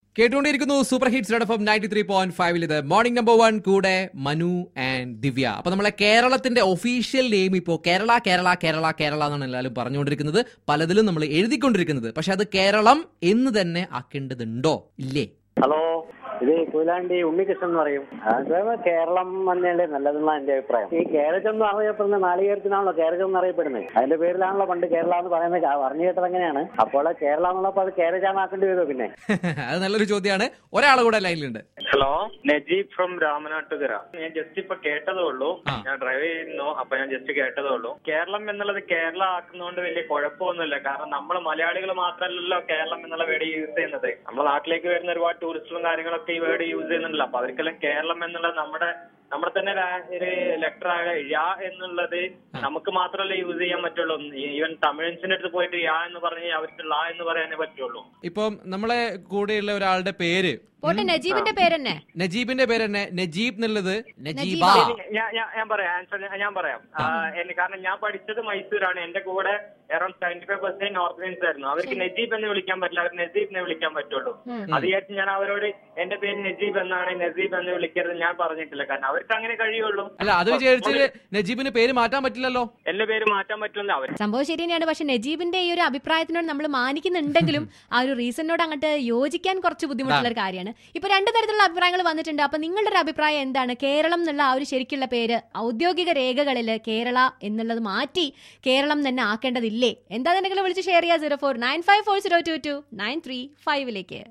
KERALA TO BE CHANGED AS KERALAM IN OFFICIAL RECORDS. CALLERS.